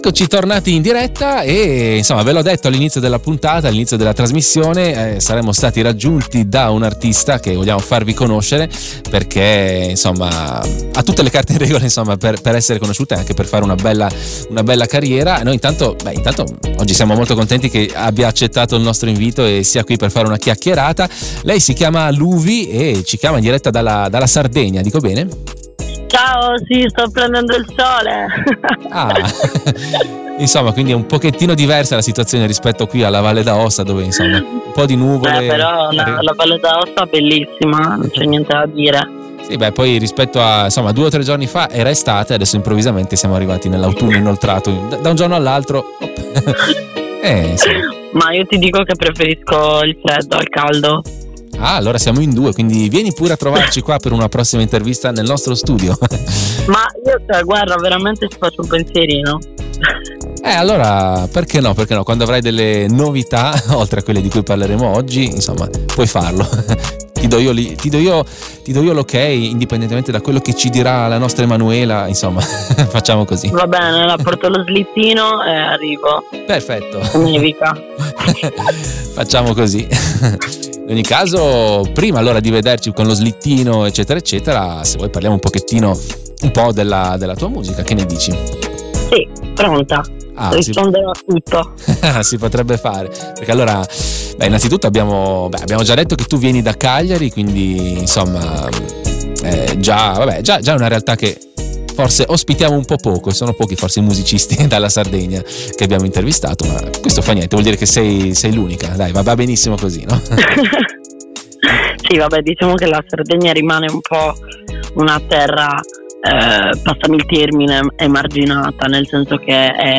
INTERVISTA-LUV.mp3